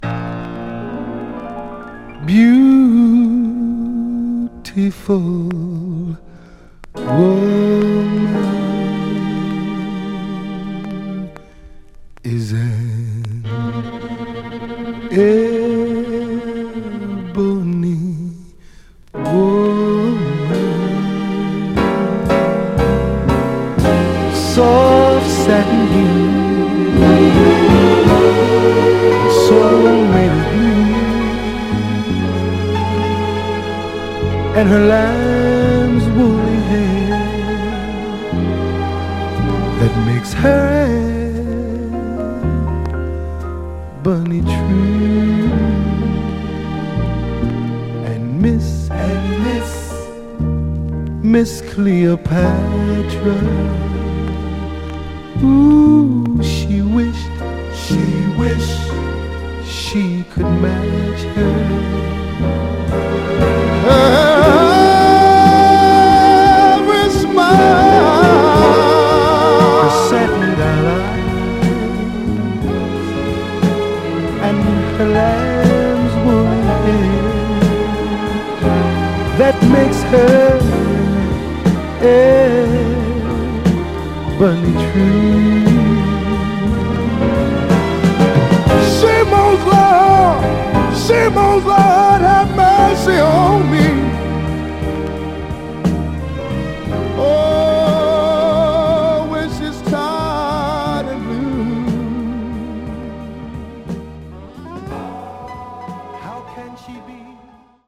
ただしとても静かな楽曲なので元々ある程度のチリつきはあります。
※試聴音源は実際にお送りする商品から録音したものです※